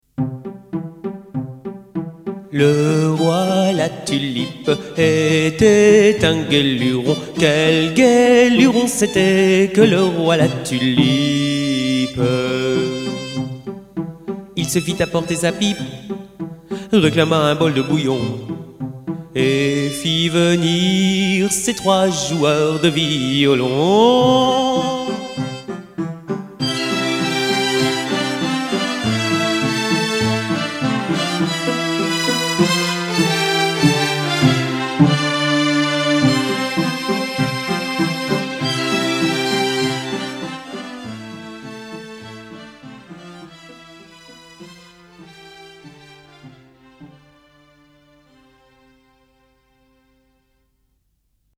Comptine de ma mère l’oie «Le roi la tulipe»